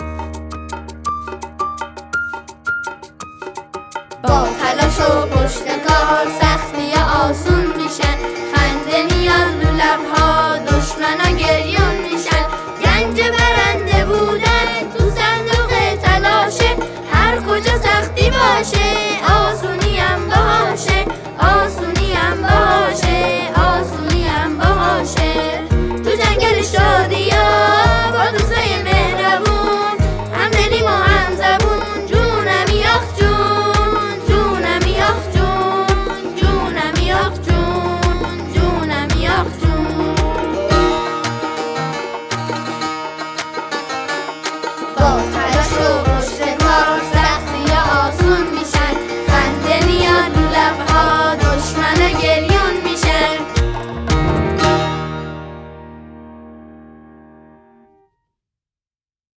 🎵 آهنگ کودکانه 🎉